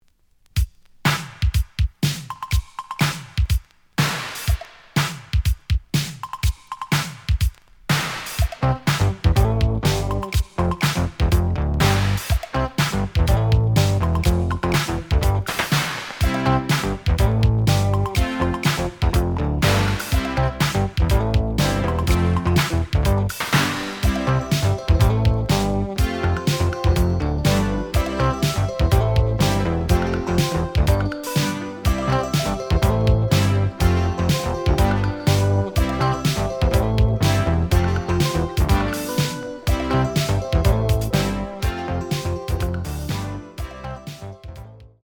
(Instrumental - Short Version)
The audio sample is recorded from the actual item.
●Genre: Disco